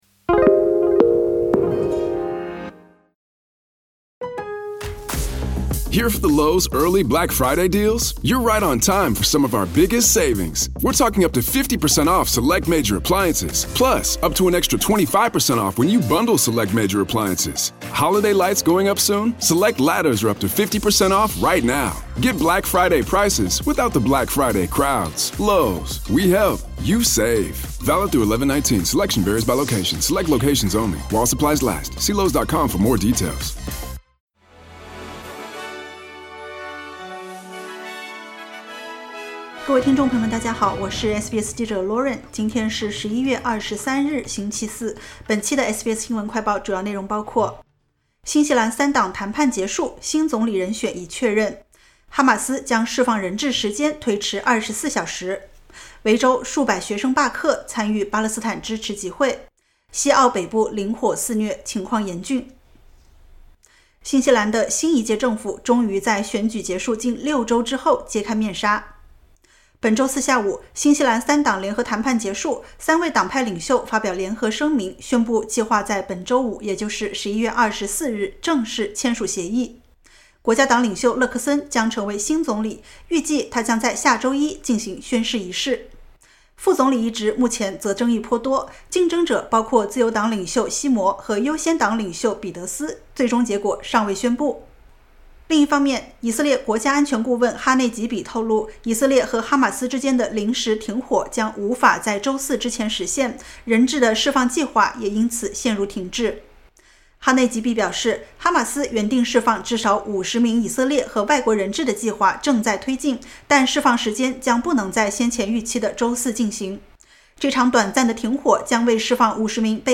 【SBS新闻快报】三党谈判结束 勒克森将成为新西兰新任总理